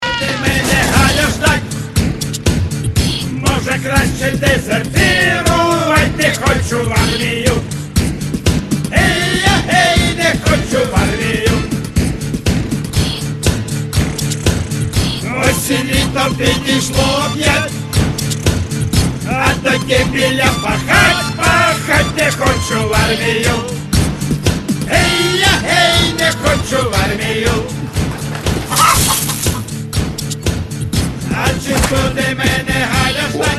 Рингтон